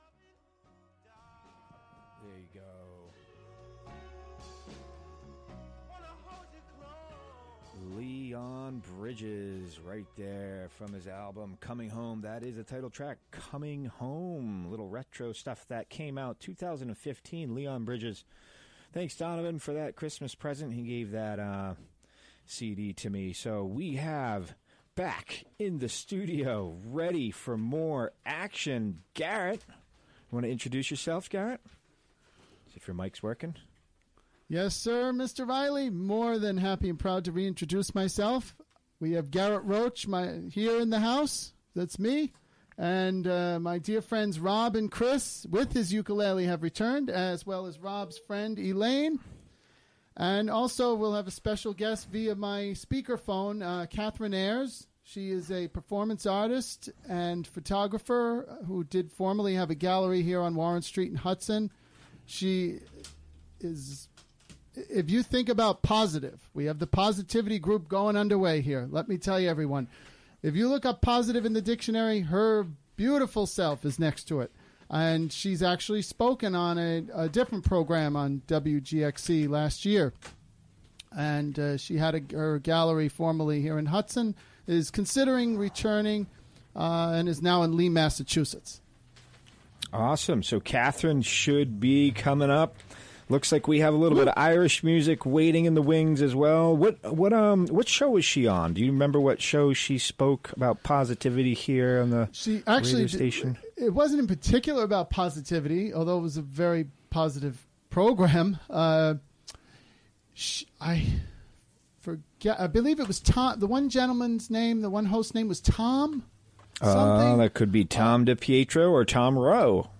Recorded during the WGXC Afternoon Show Monday, April 17, 2017.